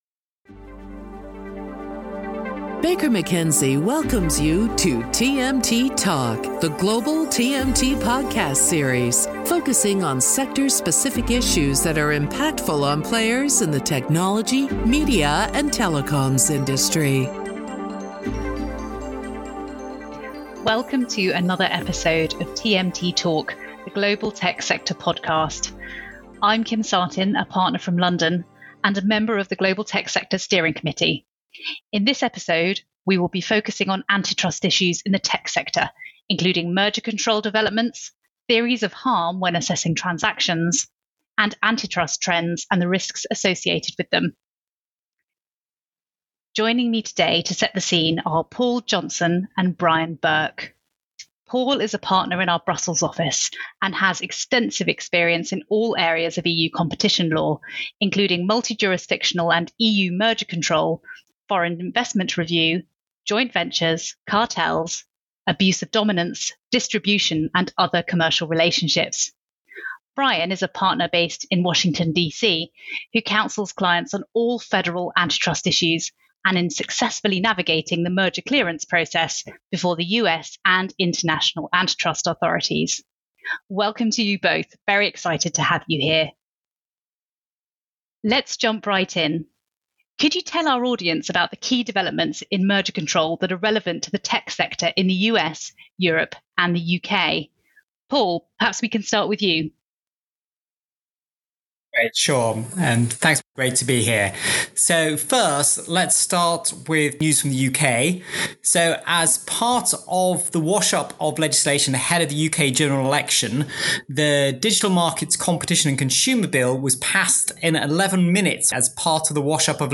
Tune in to an insightful discussion on the rapidly changing antitrust environment in the technology sector.